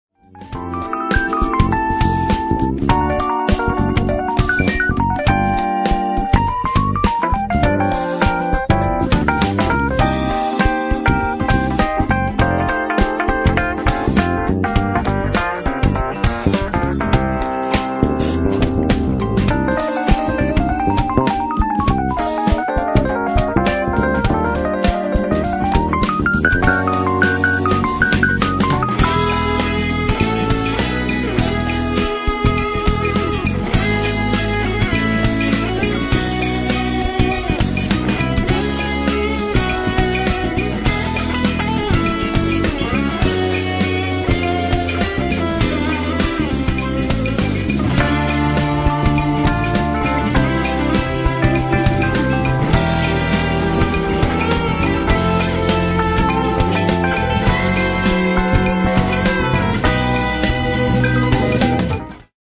e-mol, rhodes solo+odlična ritam sekcija